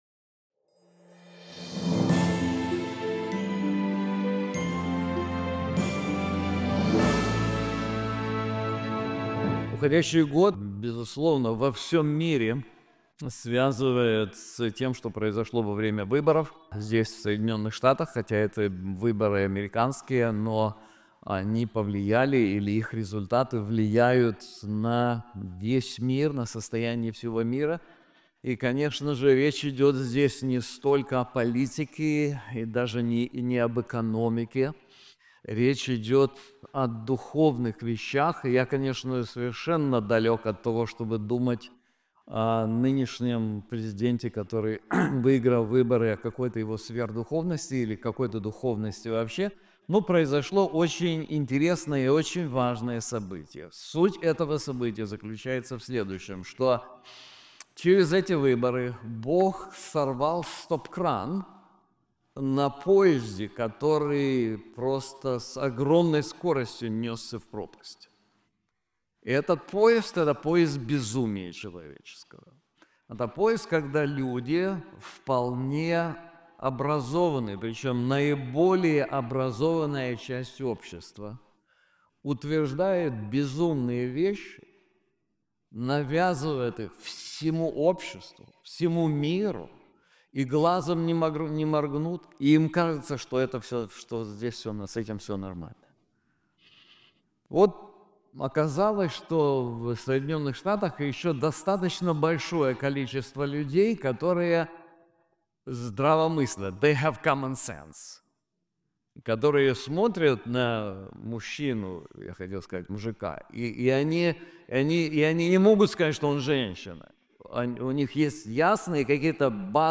This sermon is also available in English:Keep the Children Safe!